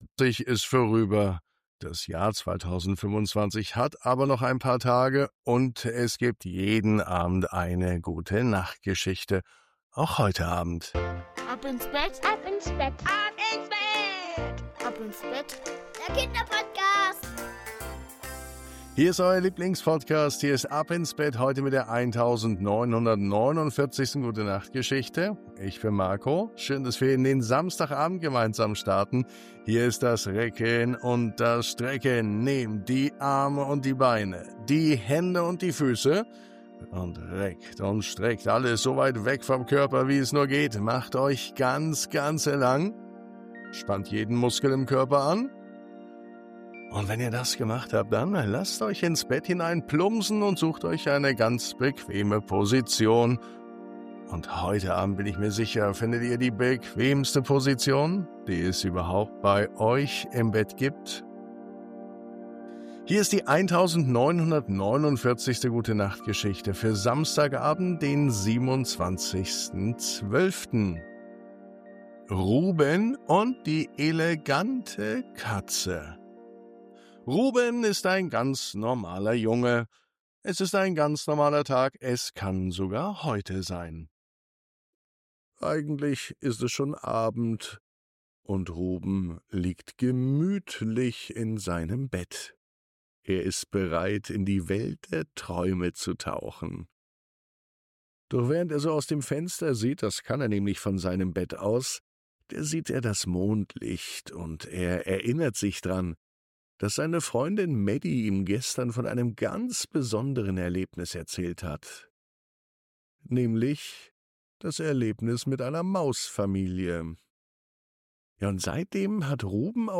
Während sie gemeinsam suchen, merkt Ruben, dass Mut manchmal ganz still beginnt. Diese Gute Nacht Geschichte schenkt Ruhe, Fantasie und ein Gefühl von Geborgenheit.